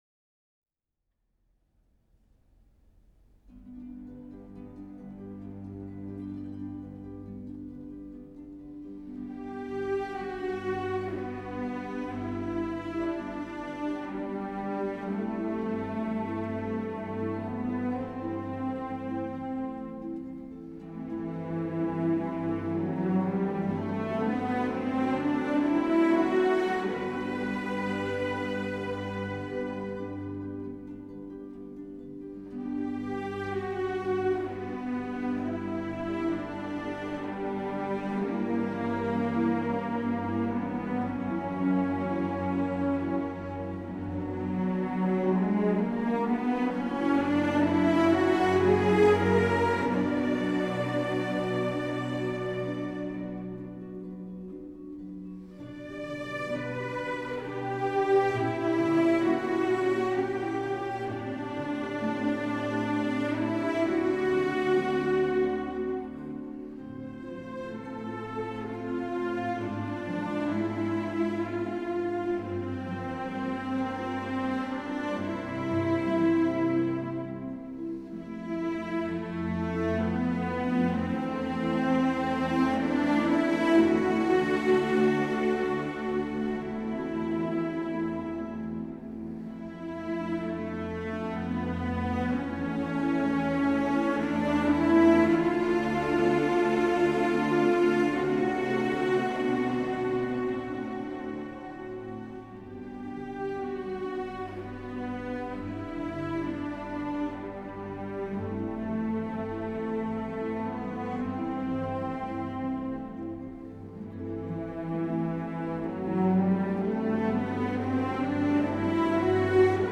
40把大提琴合奏是什么声音？
是一种气势非凡的雄亮磨弦声。由于演出者俱属高手故合作时得心应手，弦声整齐步伐划一，乐声几乎如出自一个巨型大提琴。
就音乐而言，这是一次创世纪的合作，但就录音而言，这更是一套成功的音响记录。